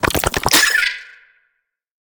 Sfx_creature_penguin_skweak_08.ogg